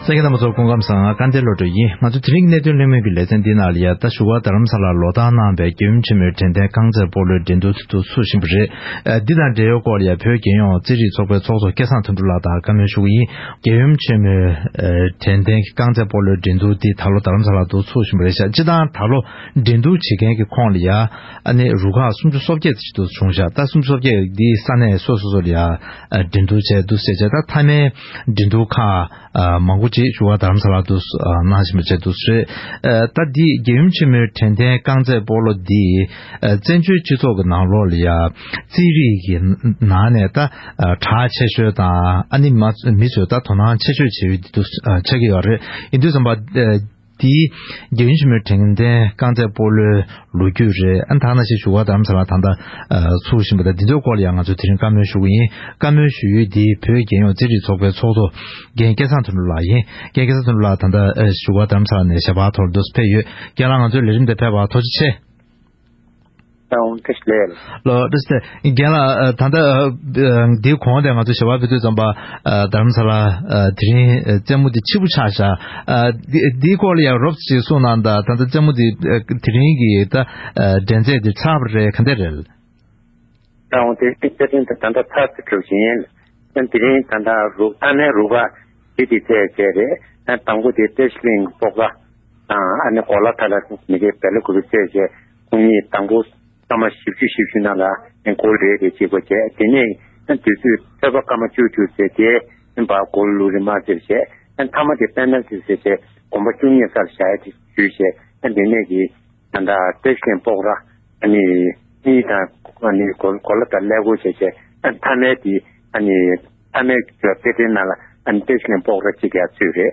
༄༅། །རྒྱལ་ཡུམ་ཆེན་མོའི་དྲན་རྟེན་རྐང་རྩེད་སྤོ་ལོའི་འགྲན་བསྡུར་དང་འབྲེལ་བའི་སྐོར་གླེང་མོལ་གནང་བ་ཞིག་གསན་རོགས་གནང་།